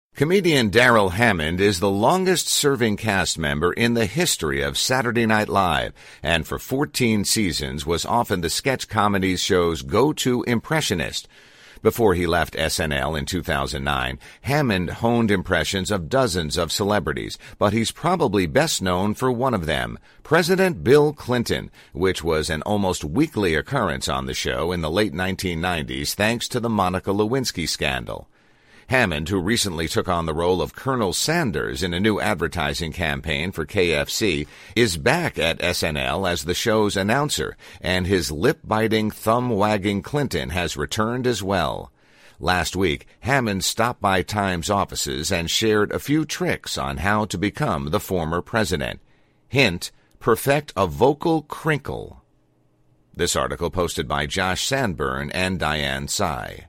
Watch Darrell Hammond Break Down His President Clinton Impression